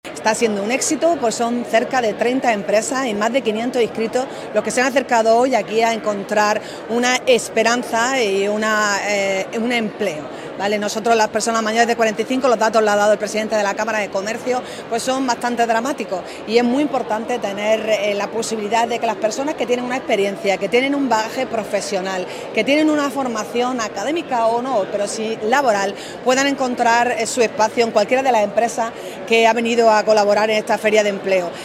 ALCALDESA-TALENTO-ENCUENTRO-45-CAMARA-COMERCIO.mp3